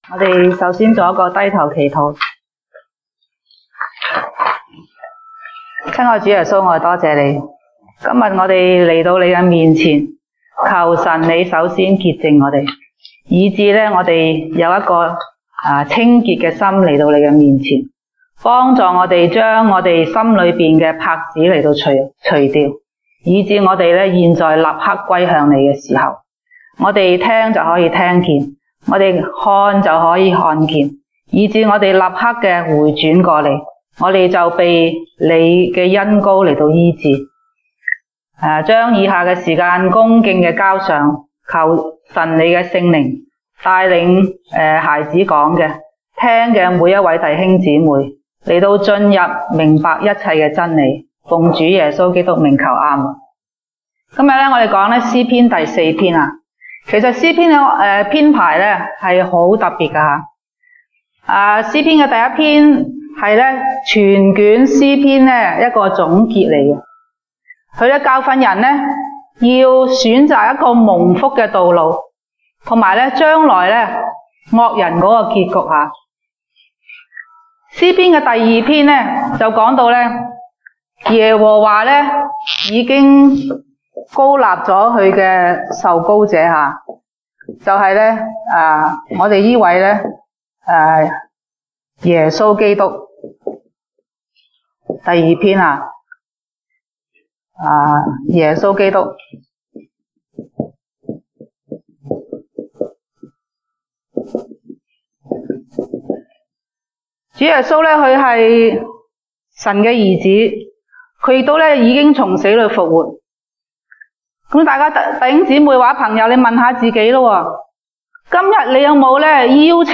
東北堂證道 (粵語) North Side: 耶和華已經分別虔誠人歸他自己 (詩篇 4:3)